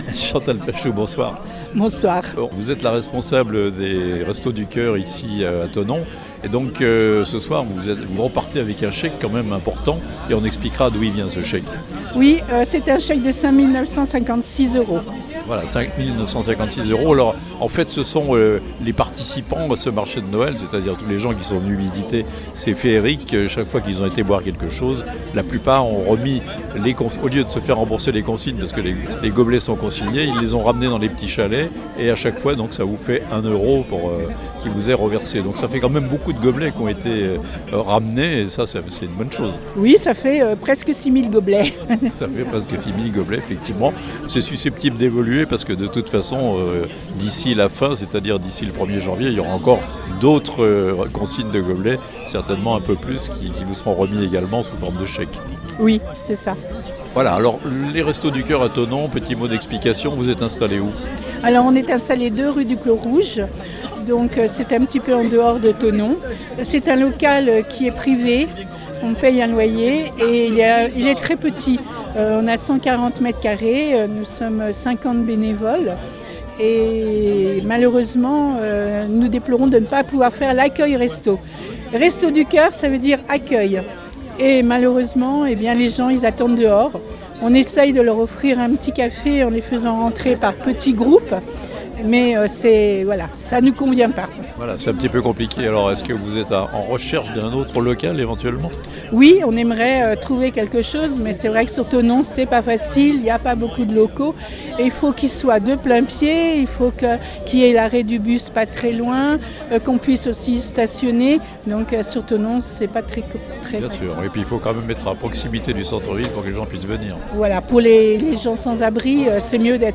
Thonon : clap de fin pour Les Féeriques et remise de chèque aux Restos du Cœur (interviews)